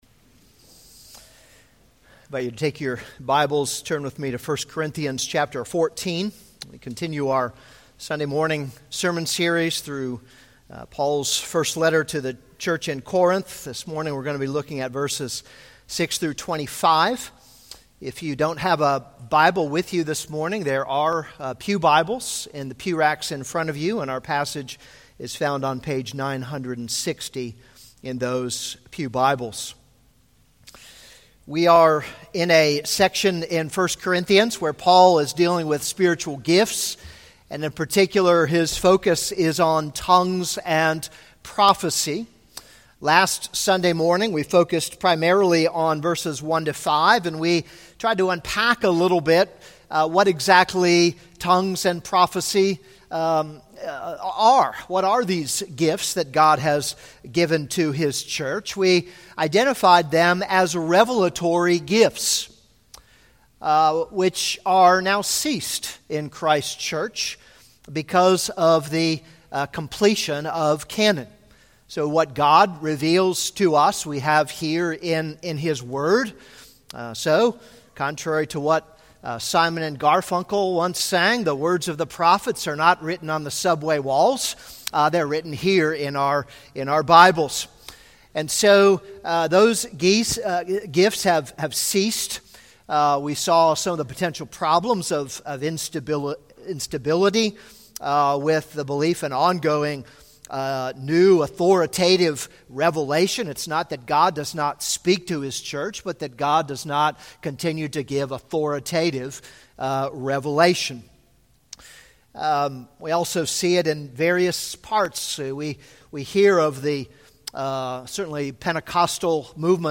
This is a sermon on 1 Corinthians 14:6-25.